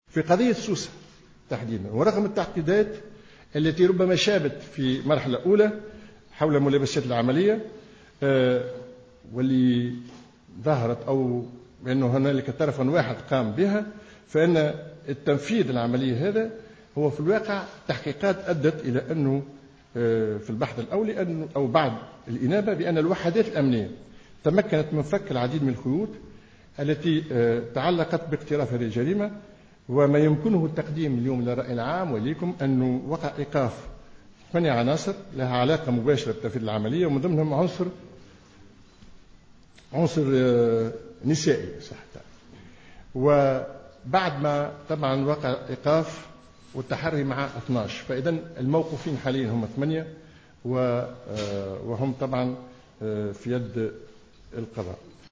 أكد الوزير لدى رئيس الحكومة المكلف بالعلاقة مع الهيئات الدستورية والمجتمع المدني كمال الجندوبي خلال ندوة صحفية عقدت اليوم الخميس 2 جويلية 2015 بقصر الحكومة بالقصبة بخصوص الإدلاء بمعطيات جديدة حول هجوم سوسة الارهابي أن الوحدات الأمنية تمكنت من إيقاف 8 عناصر على علاقة مباشرة بتفيذ العملية منها عنصر نسائي و ذلك بعد التحري مع 12 شخصا.